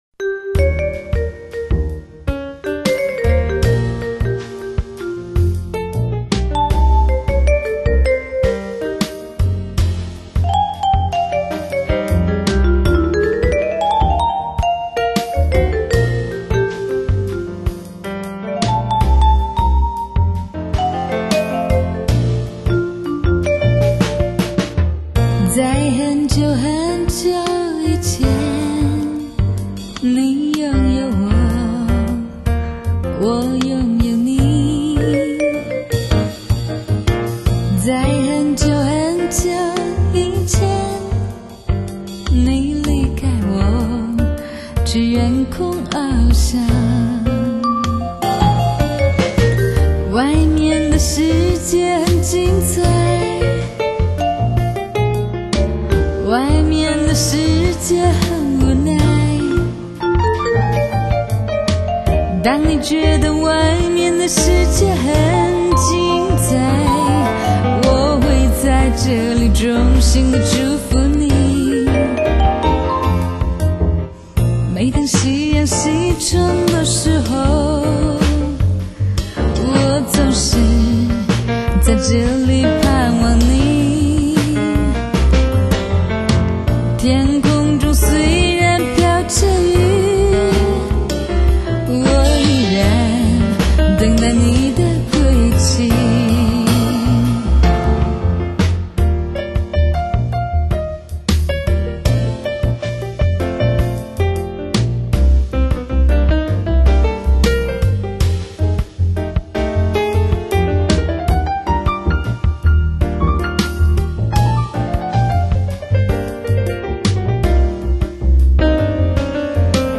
爵士风味